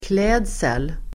Uttal: [kl'ed:sel el. kl'ä:dsel]